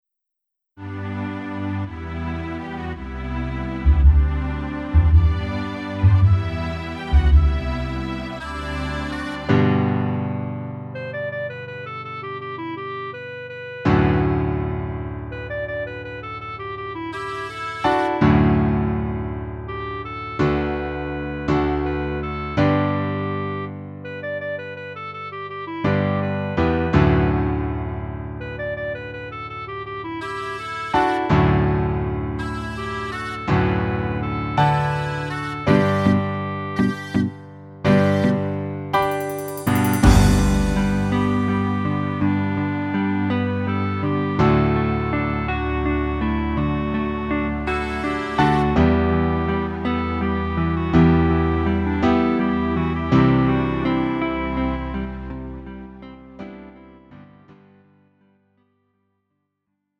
음정 -1키 3:39
장르 가요 구분 Lite MR